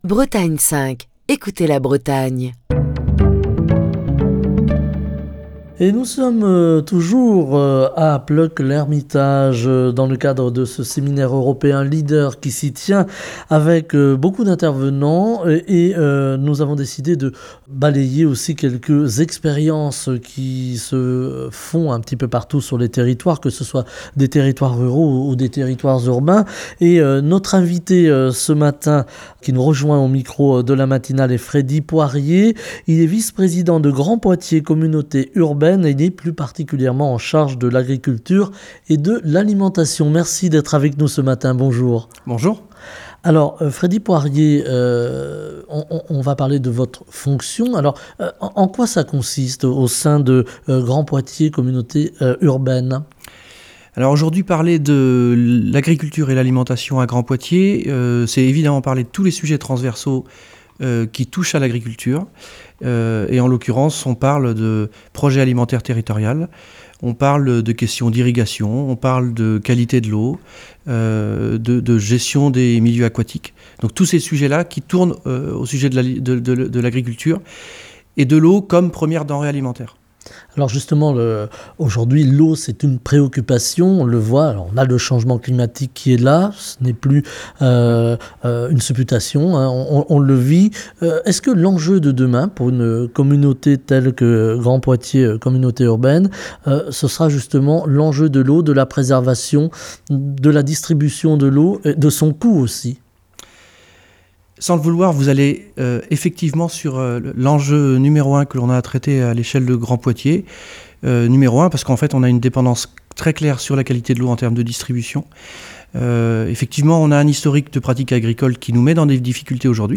Semaine Europe et ruralité - Bretagne 5 est en direct de Plœuc-L'Hermitage pour le séminaire LEADER.